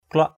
kluak.mp3